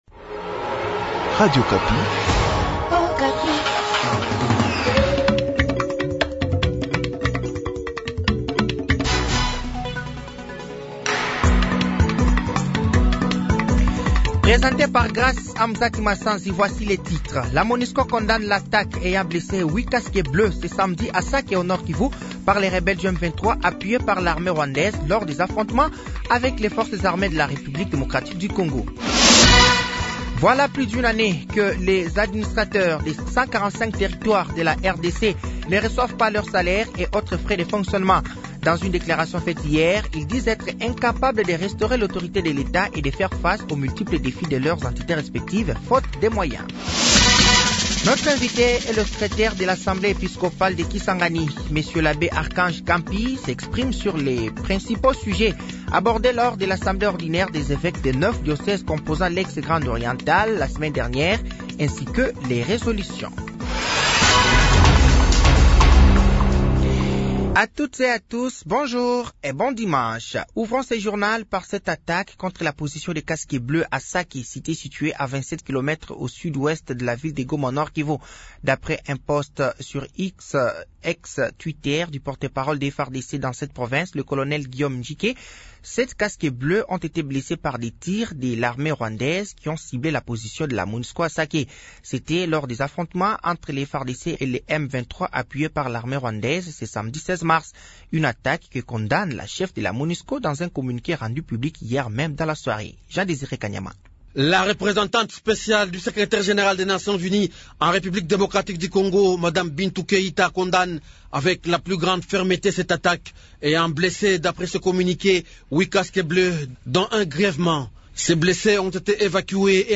Journal matin
Journal français de 7h de ce dimanche 17 mars 2024